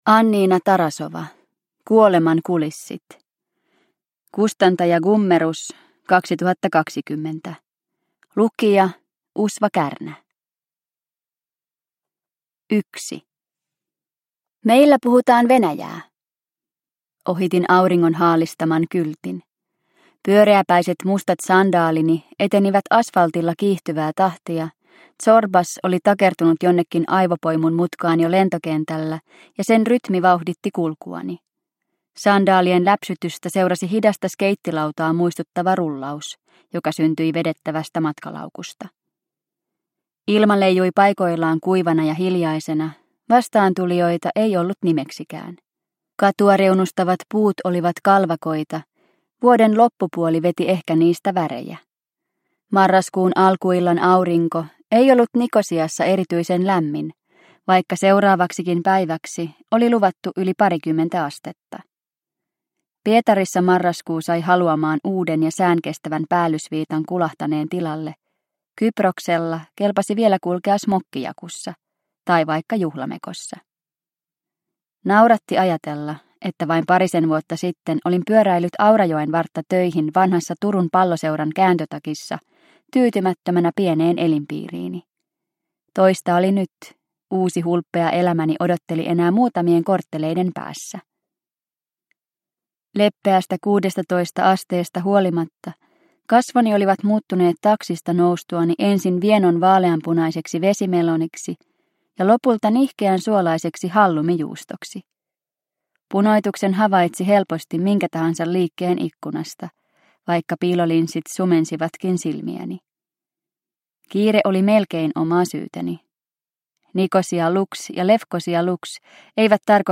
Kuoleman kulissit – Ljudbok – Laddas ner